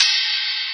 archived music/fl studio/drumkits/bvker drumkit/Cymbals/Rides